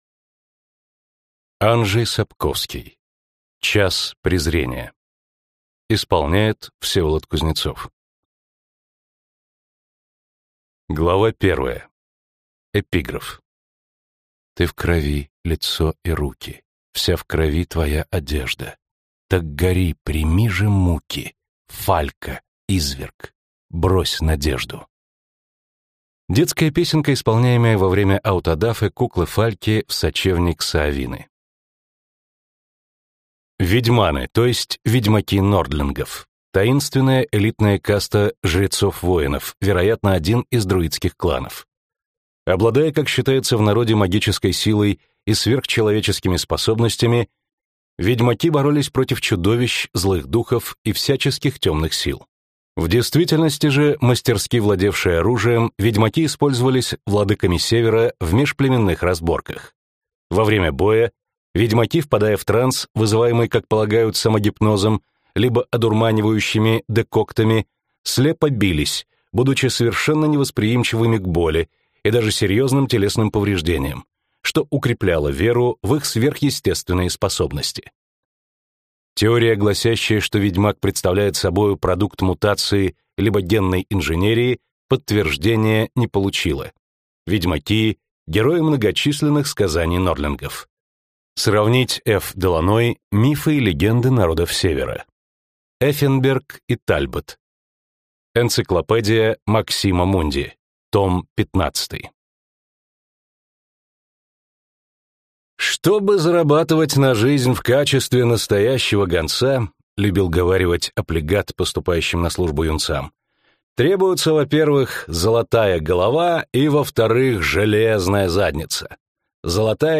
Аудиокнига Час Презрения | Библиотека аудиокниг